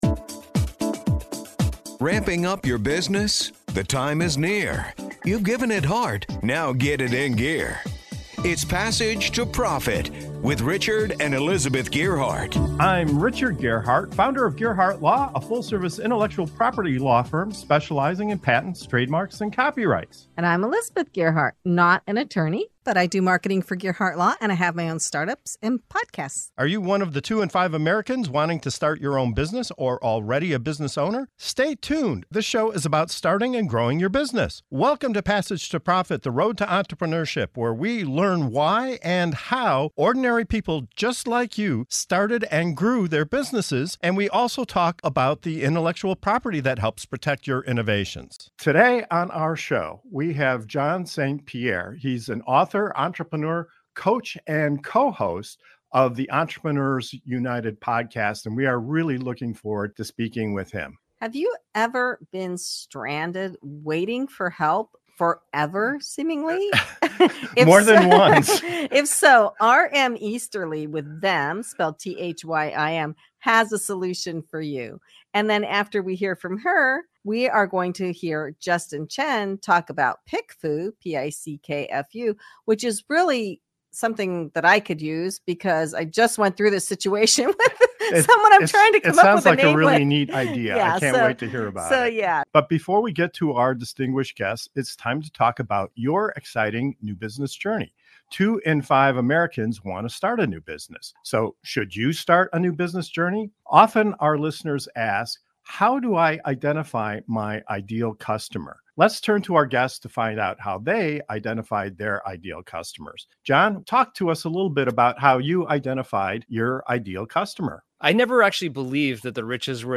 The Passage to Profit Show interview